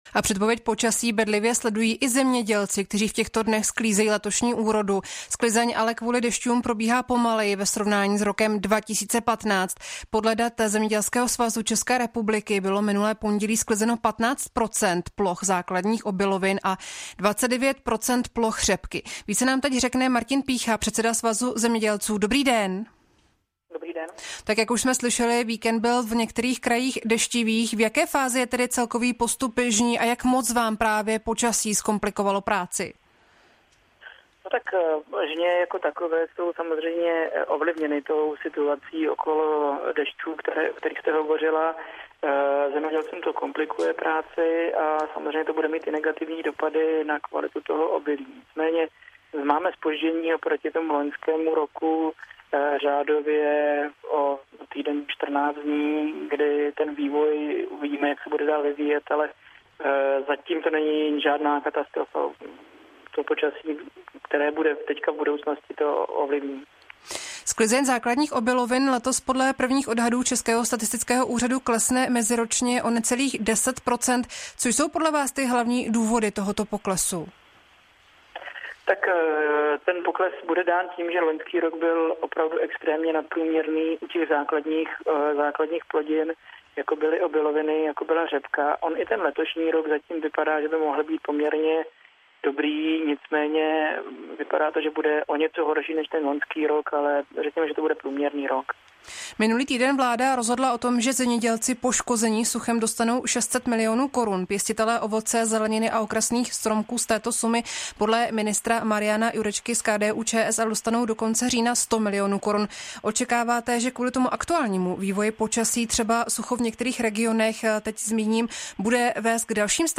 ČR Plus: Rozhovor s předsedou svazu na téma letošní úrody